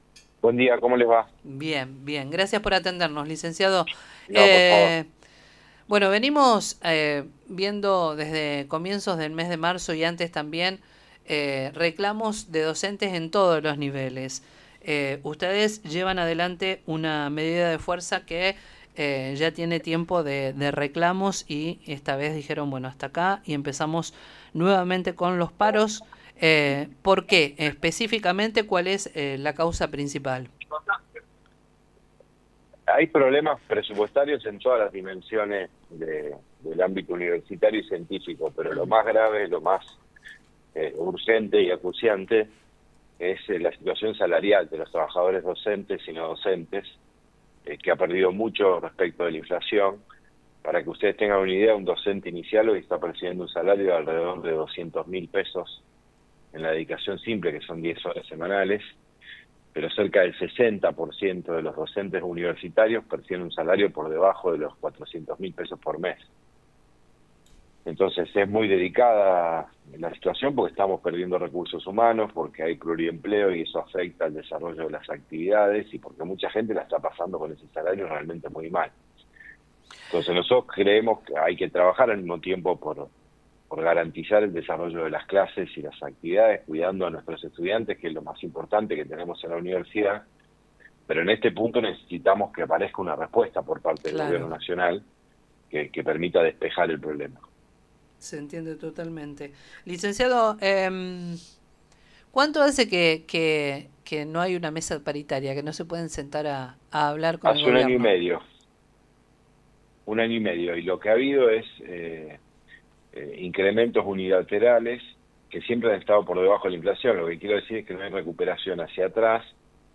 En contacto con el programa “Con Voz” de FM 102.9 Radio Nueva Estrella, el rector de la Universidad Nacional de Rosario, Franco Bartolacci, expresó su preocupación por la situación que atraviesan los trabajadores docentes.